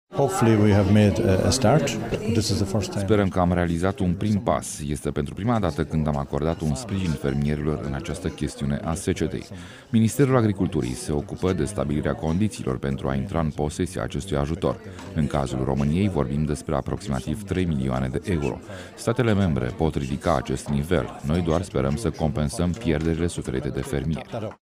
Aflat la Dublin, în Irlanda, comisarul european pentru agricultură, Phil Hogan, a precizat, în exclusivitate pentru Radio Iaşi, că banii reprezintă o parte din ajutorul de 500 de milioane de euro acordat statelor membre: